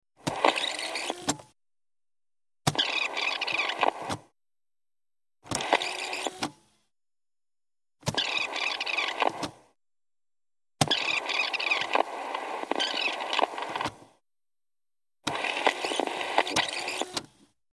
Answering Machine Movement 4; Several Scans ( Forwards And Backwards ); A Forward And Backward Scan Leading Into A Long Backwards And A Medium Long Forward Scan. Close Perspective.